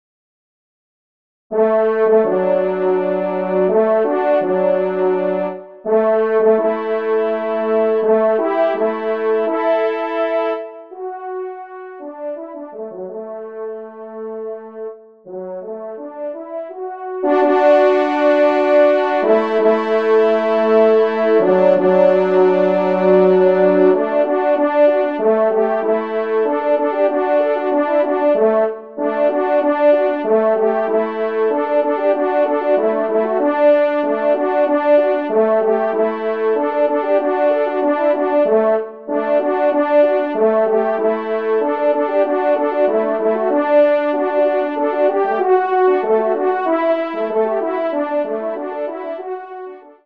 2e Trompe